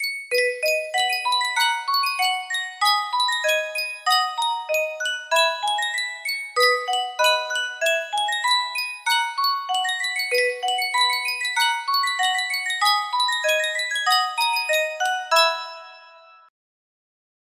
Yunsheng Music Box - Brahms Clarinet Trio 6006 music box melody
Full range 60